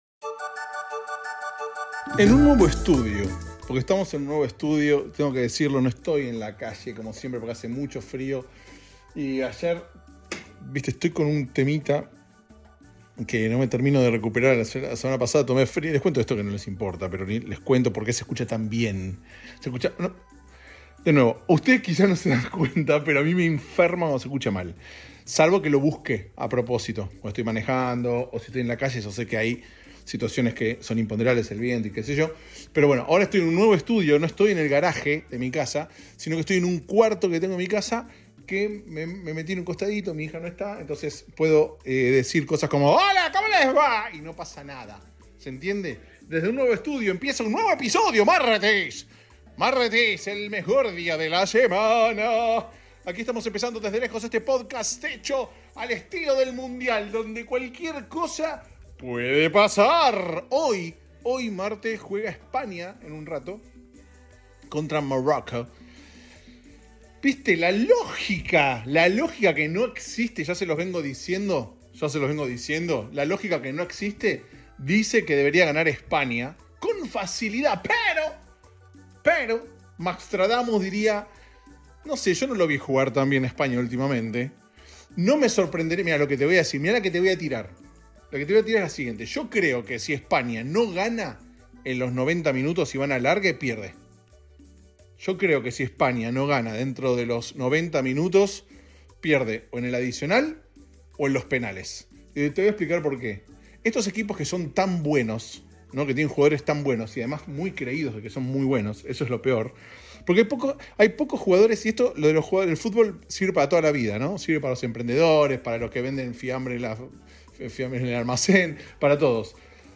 En medio de la fiebre mundialista, el podcast se graba en estudio nuevo.